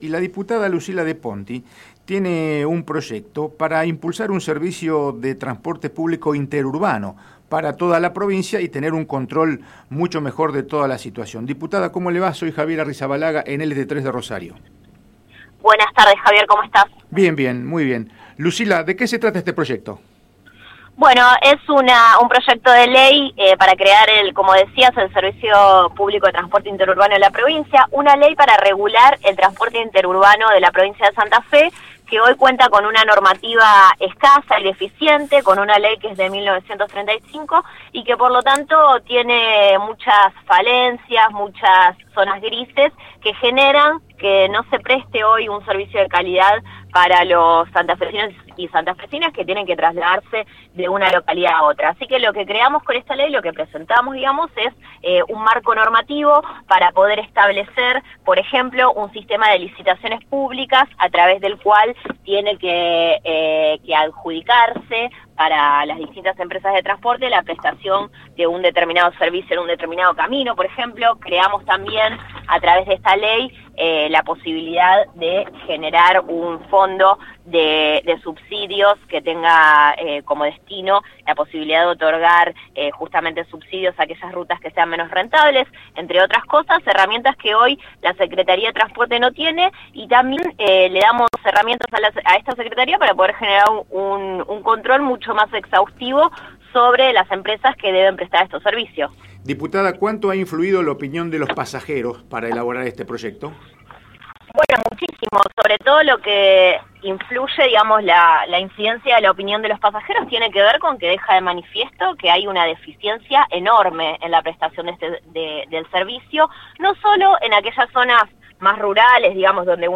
Por el Río Suena, De Ponti, dio más detalles sobre su proyecto.